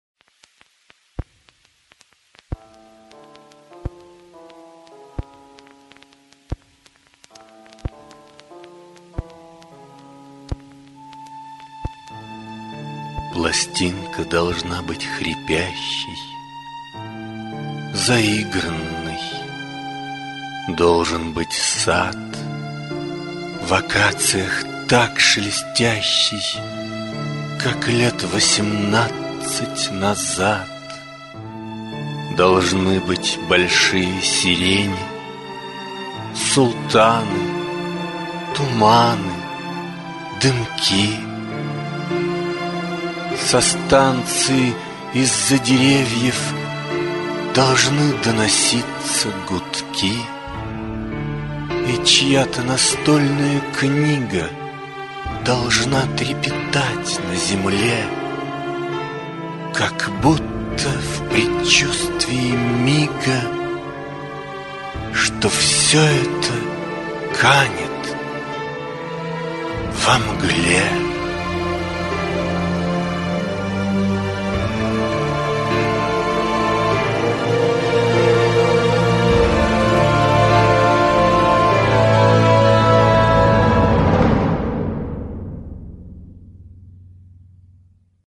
«Пластинка должна быть хрипящей…» в жанре мелодекламации.